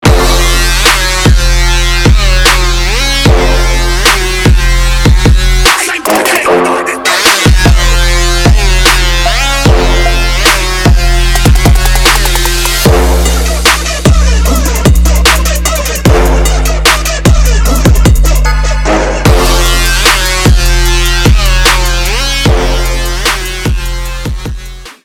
• Качество: 320, Stereo
громкие
Electronic
Trap
качающие
Bass